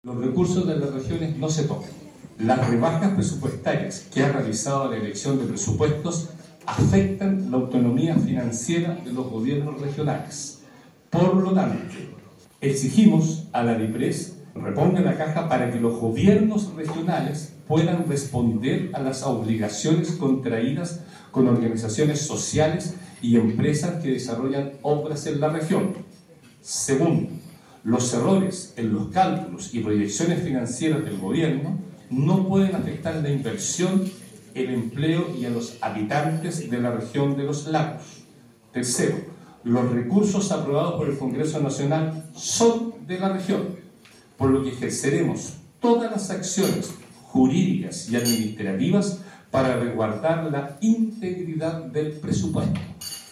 En la última sesión del Consejo Regional de Los Lagos, realizada en Puerto Montt, el Gobernador Patricio Vallespín leyó una declaración contundente en la que expresó la molestia de la región ante los recortes presupuestarios implementados por la Dirección de Presupuestos.